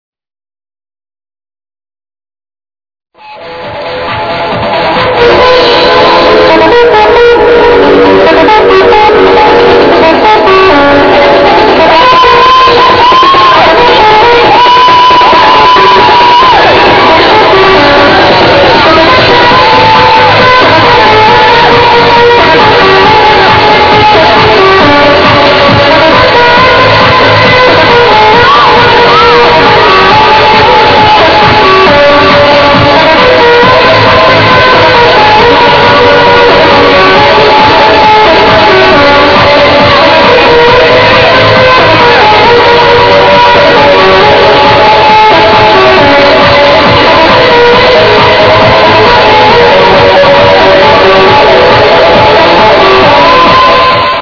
Hard to tell with that woman having an orgasm by the mic